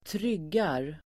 Uttal: [²tr'yg:ar]